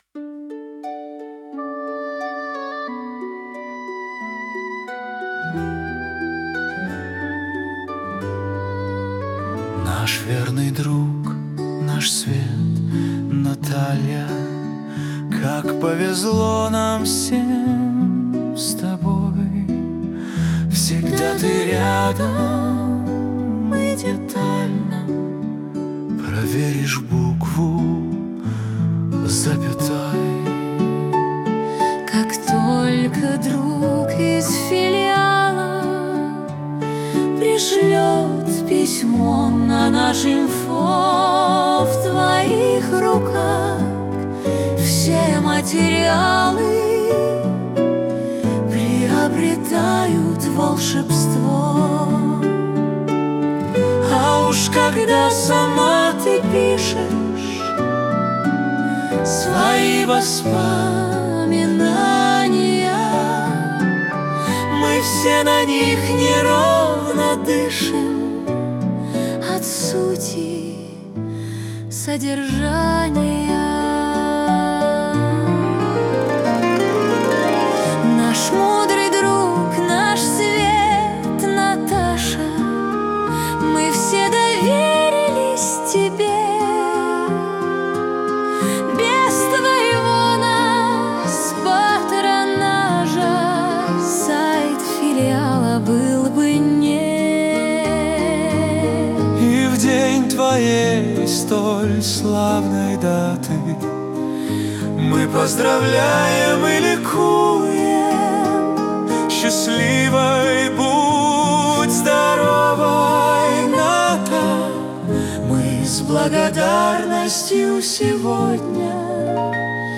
Романс: